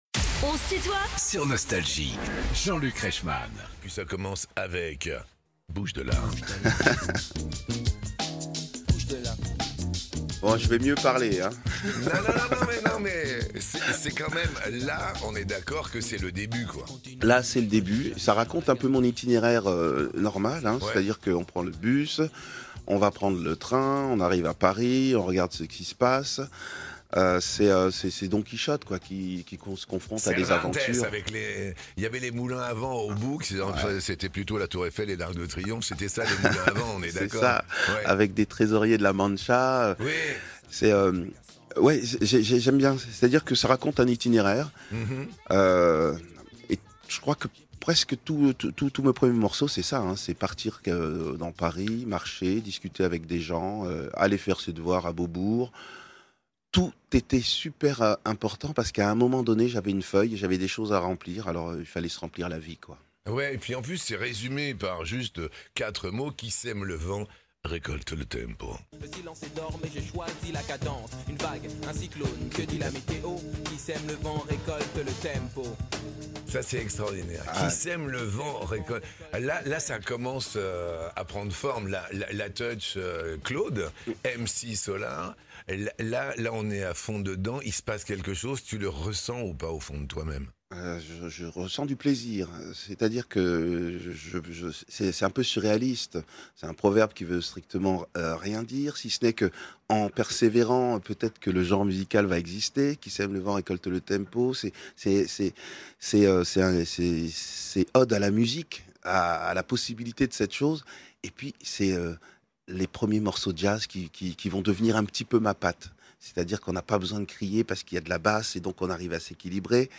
MC Solaar est l'invité de "On se tutoie ?..." avec Jean-Luc Reichmann
Les plus grands artistes sont en interview sur Nostalgie.